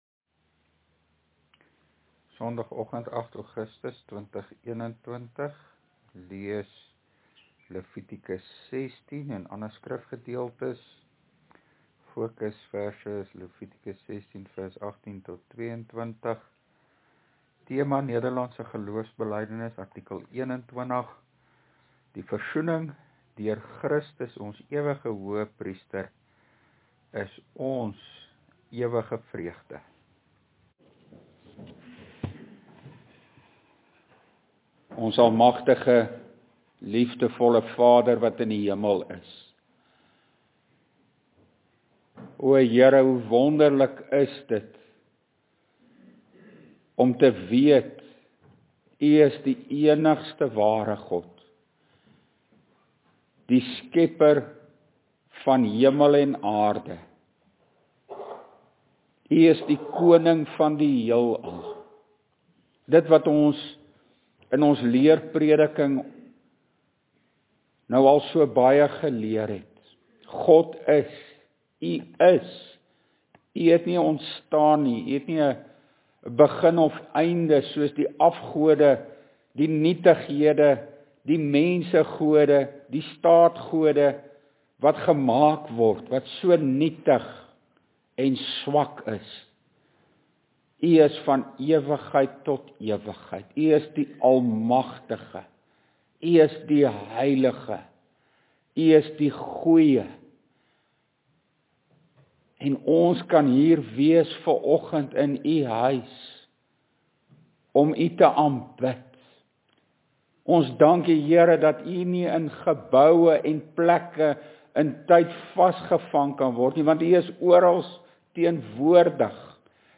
LEERPREDIKING: NGB artikel 21